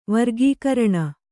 ♪ vargīkaraṇa